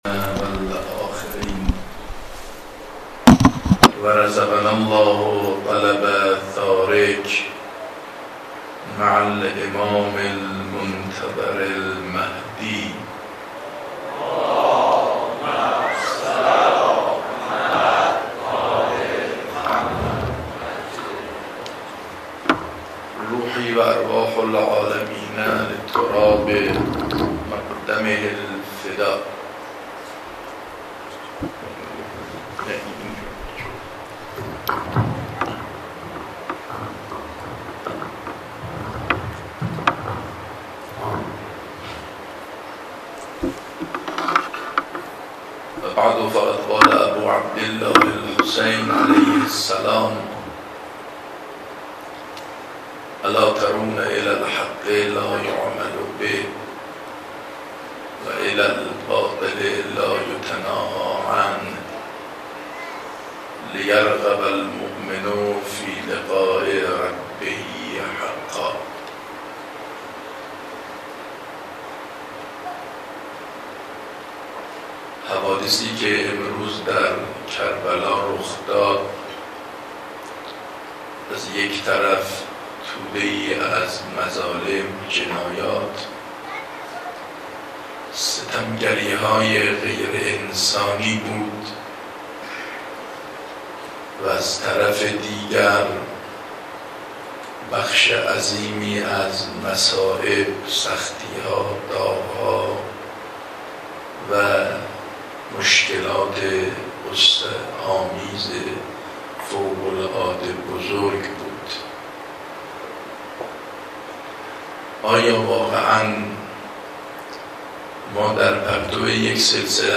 شب شام غریبان دانشگاه فردوسی